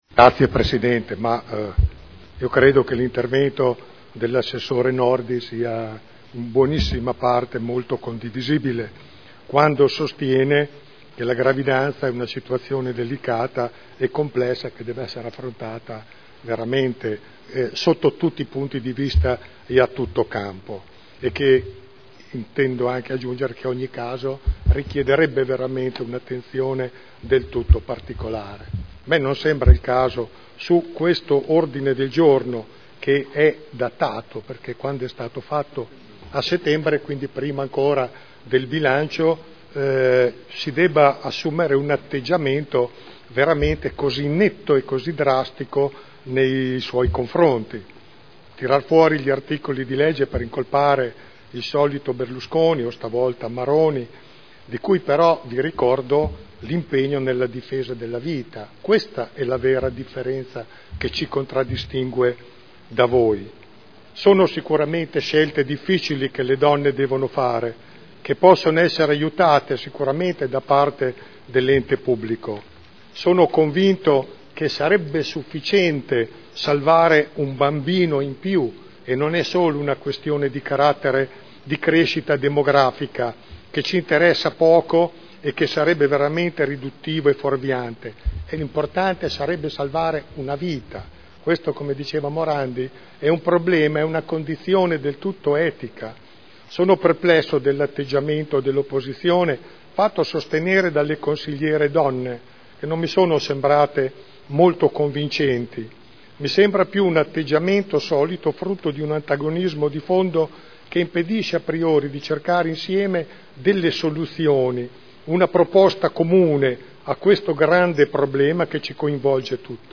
Gian Carlo Pellacani — Sito Audio Consiglio Comunale